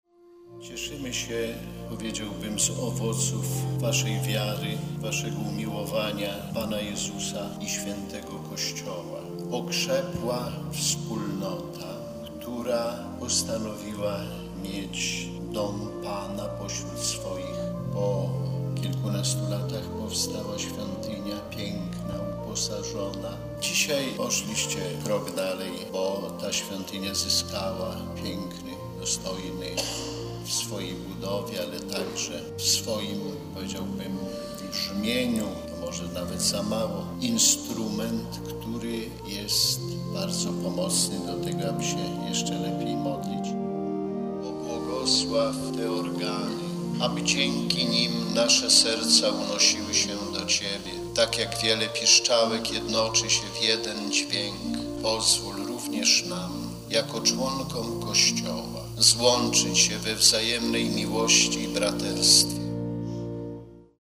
Parafia Świętej Rodziny na Zaciszu ma nowe organy. Poświęcenia instrumentu dokonał podczas niedzielnej Eucharystii o godzinie 12:00 ordynariusz diecezji warszawsko-praskiej, bp Romuald Kamiński.
obr_organy_zacisze.mp3